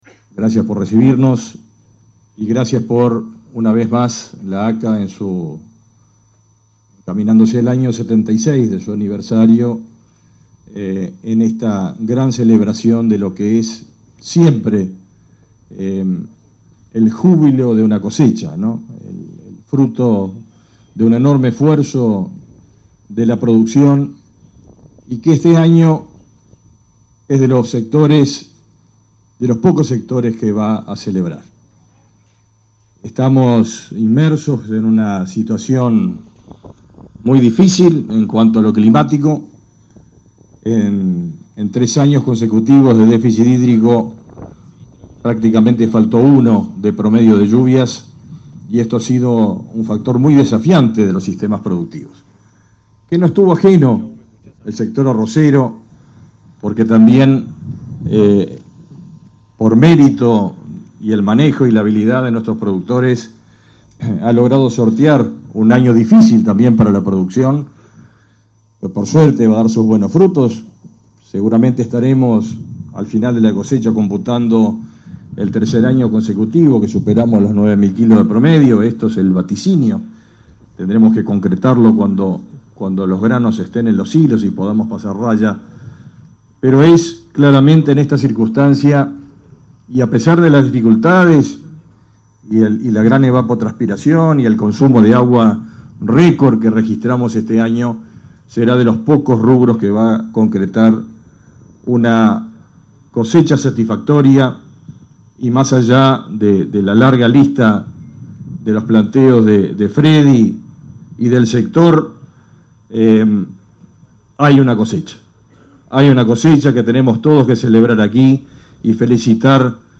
Palabras del ministro de Ganadería, Fernando Mattos
El ministro de Ganadería, Fernando Mattos, participó este lunes 6 en Rivera en la inauguración de la cosecha de arroz 2023.